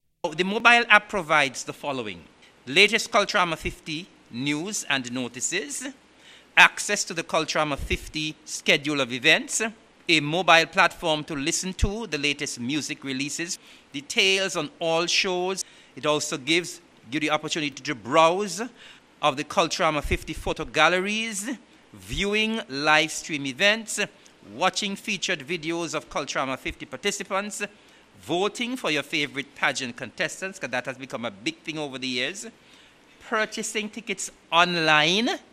During an update, Minister of Culture, Hon. Eric Evelyn spoke on all the benefits the software offers: